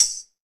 Index of /90_sSampleCDs/EdgeSounds - Drum Mashines VOL-1/M1 DRUMS
MTAMBOURIN18.wav